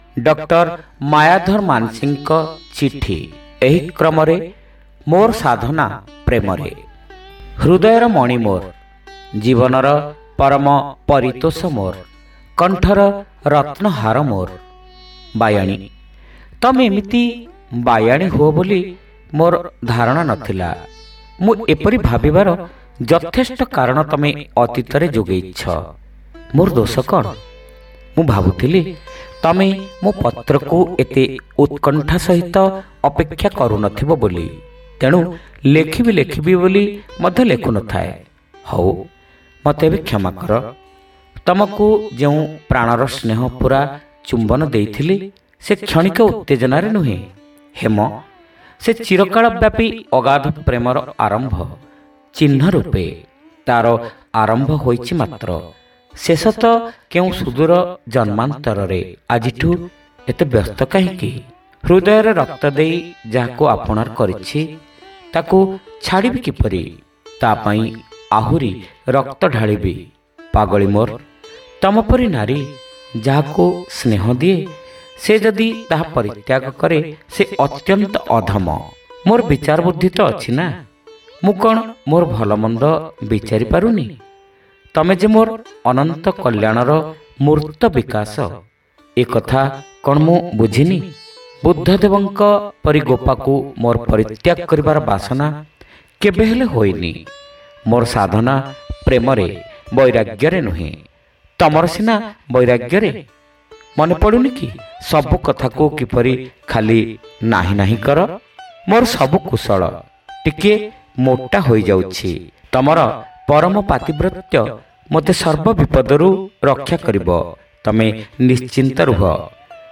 ଶ୍ରାବ୍ୟ ଗଳ୍ପ : ମୋର ସାଧନା ପ୍ରେମରେ